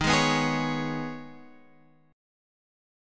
D# 7th Suspended 4th Sharp 5th
D#7sus4#5 chord {11 11 9 x 9 9} chord